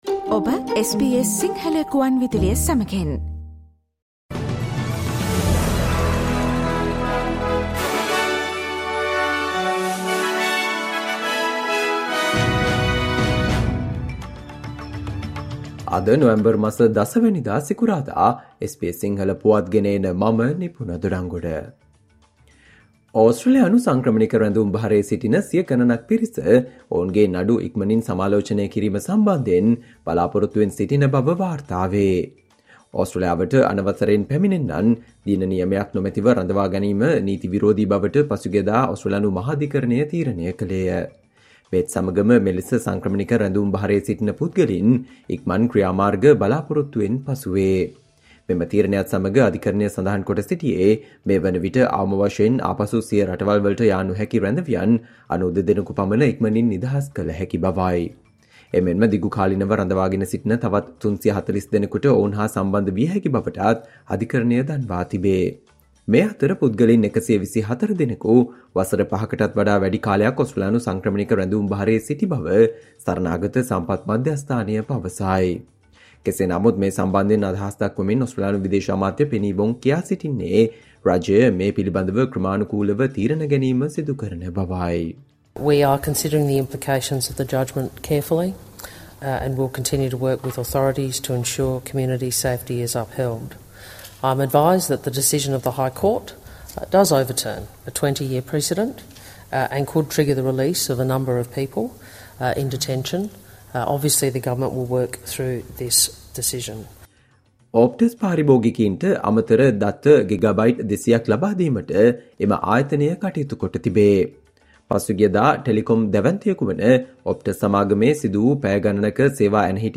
Australia news in Sinhala, foreign and sports news in brief - listen Sinhala Radio News Flash on Friday 10 November 2023.